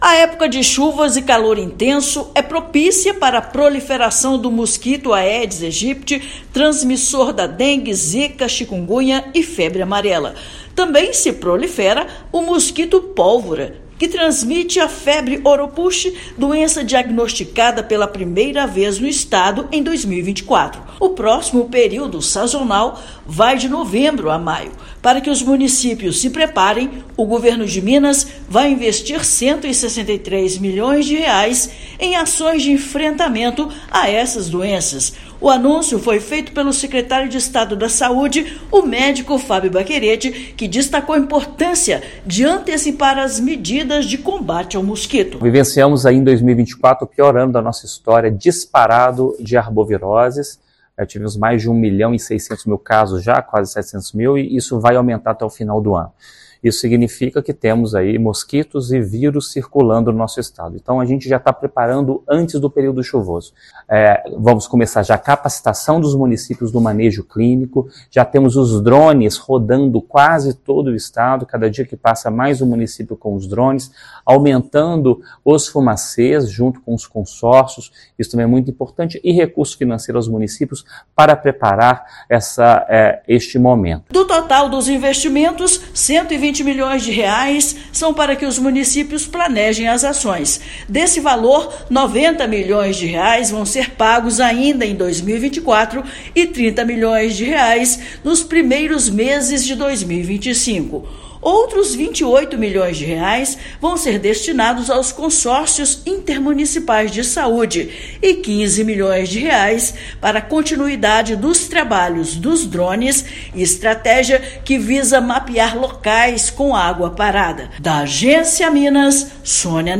Prevenção e atuação nas regionais integram preparo para época de maior incidência de doenças como dengue, chikungunya e febre oropouche. Ouça matéria de rádio.